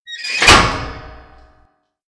CHQ_SOS_cage_door.ogg